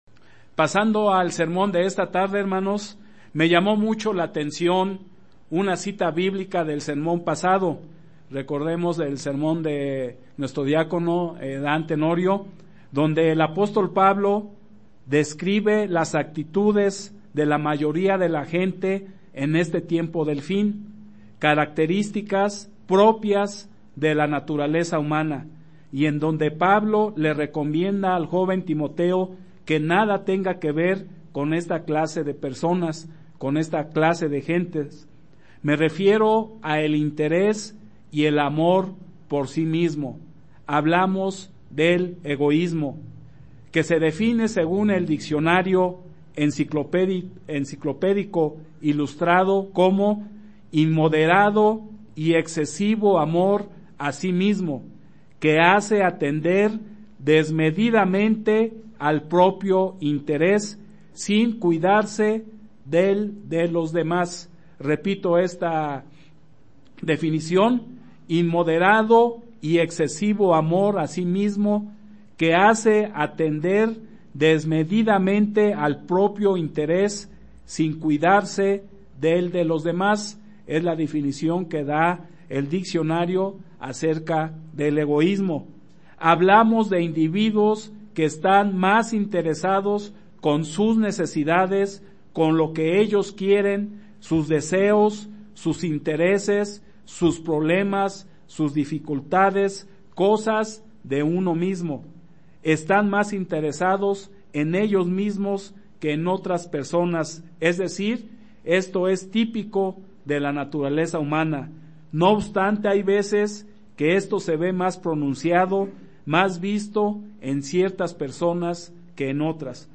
Given in Ciudad de México